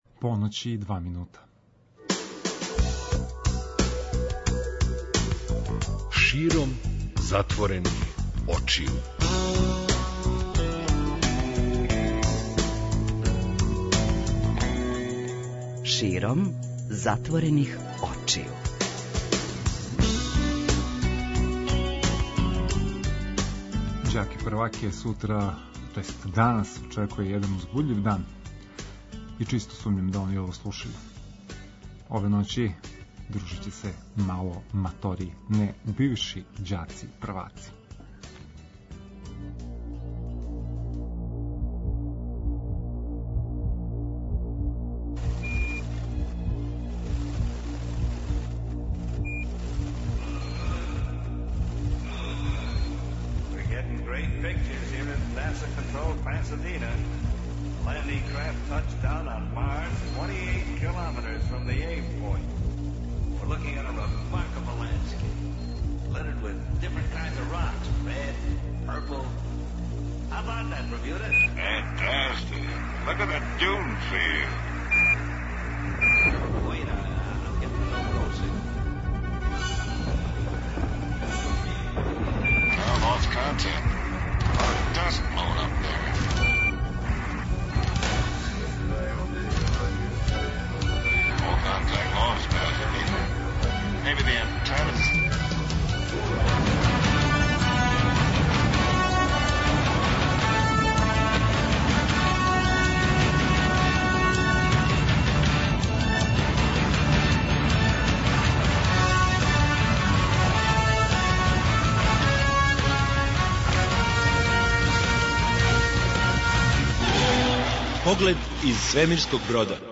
Током ове ноћи преносимо вам део те неформалне атмосфере и најразличитијих прича.